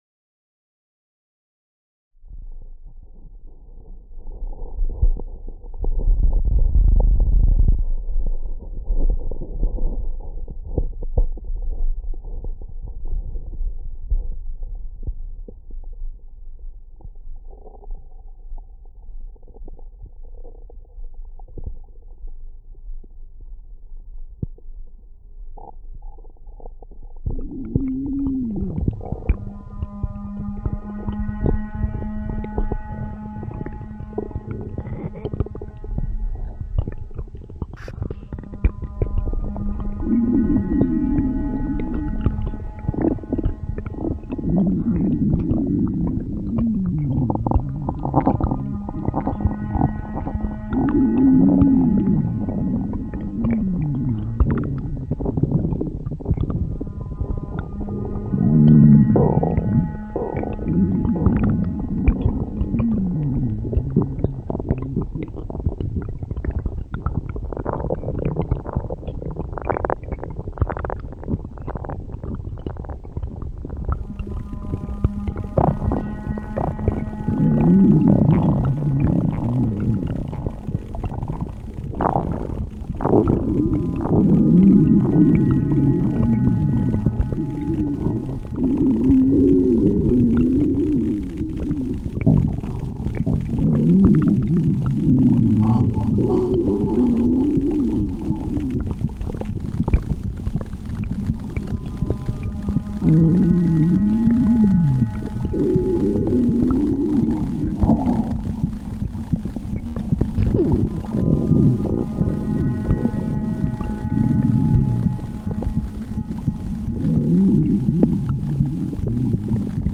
symbiózy elektronických nástrojov s akustickými zvukmi